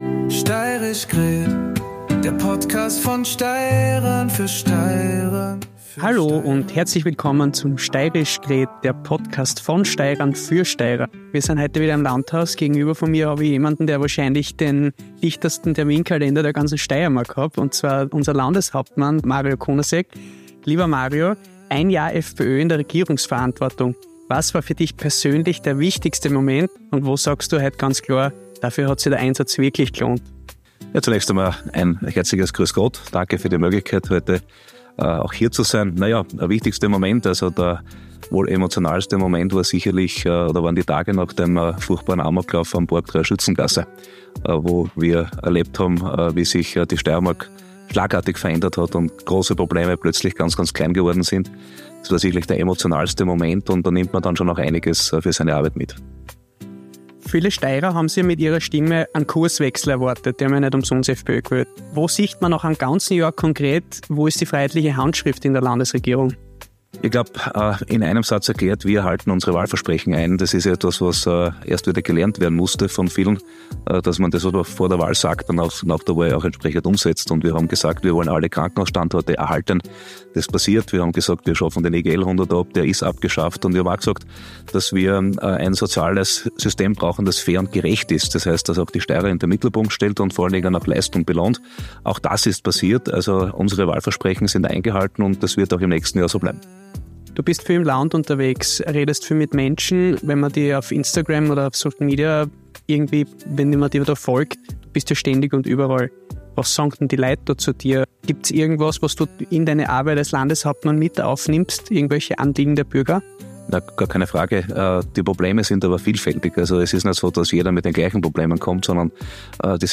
In dieser Folge von „Steirisch gred“ ist Landeshauptmann Mario